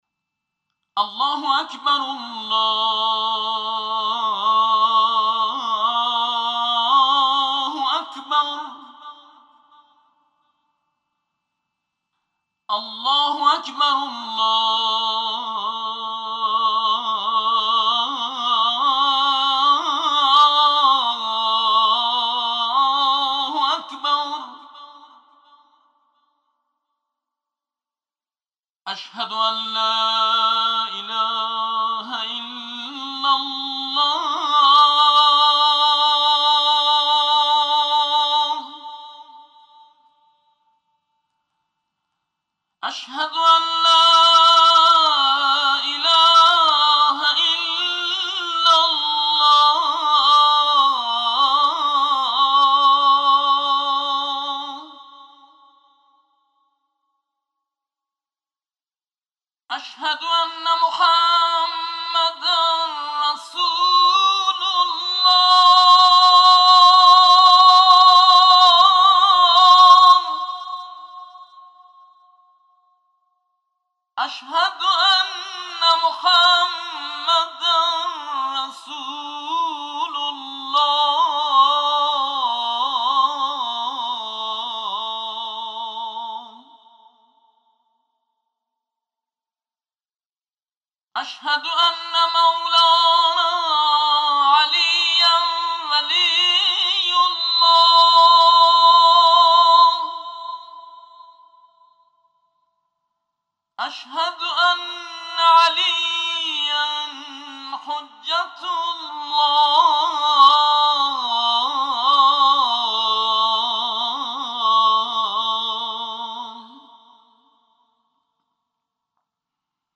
صوت/ طنین اذان با صدای دلنشین مرحوم «موسوی قهار»
صوت/ طنین دلنشین اذان با صدای مرحوم «موسوی قهار»
برچسب ها: خبرگزاری قرآن ، ایکنا ، سید قاسم موسوی قهار ، موسوی قهار ، دعای سحر ، قرآن ، iqna ، اذان موسوی قهار